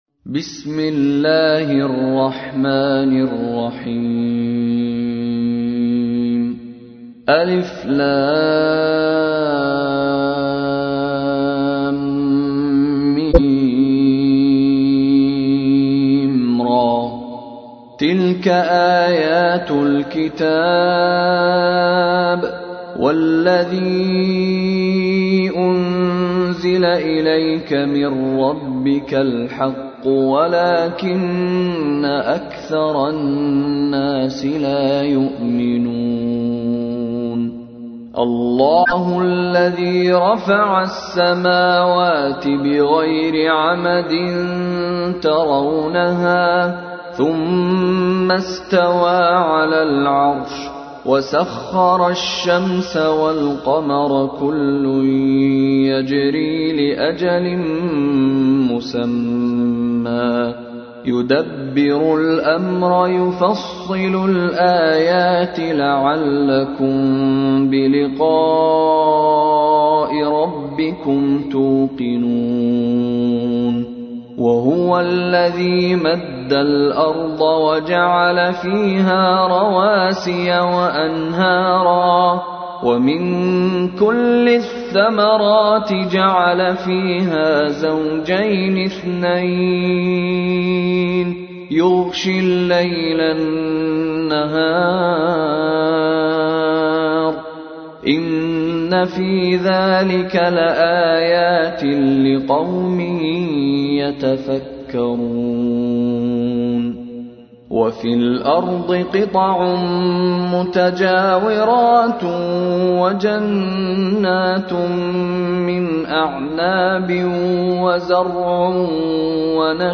تحميل : 13. سورة الرعد / القارئ مشاري راشد العفاسي / القرآن الكريم / موقع يا حسين